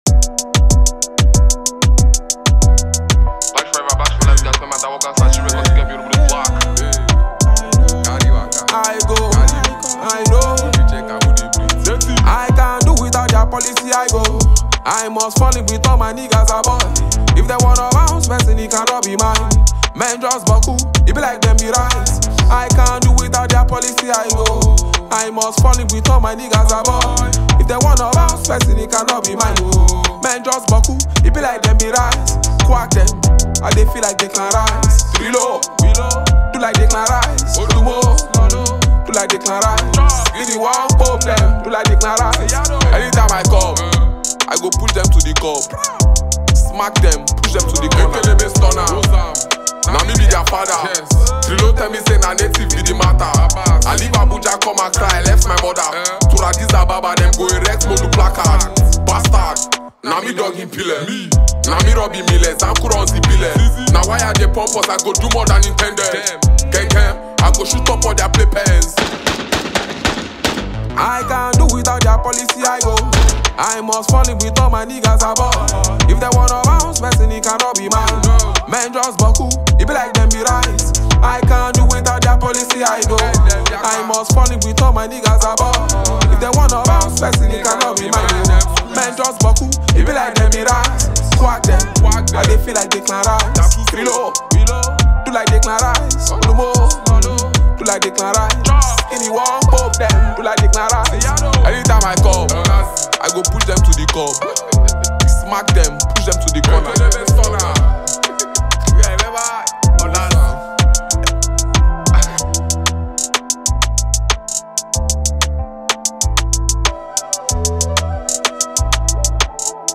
Nigerian rapper and singer